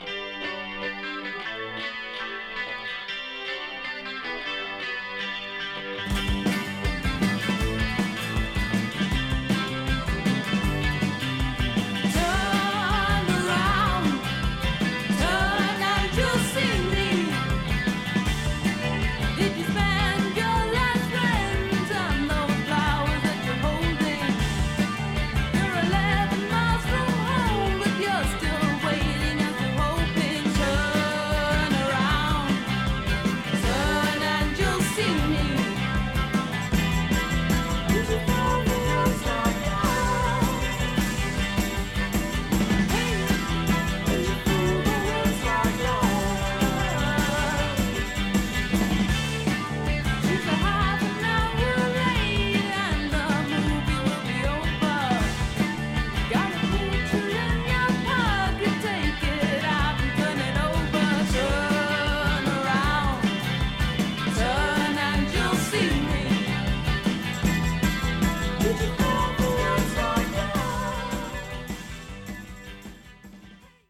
サイケデリック的フィーリングに軽快なギターサウンドがその手の玄人好きに人気。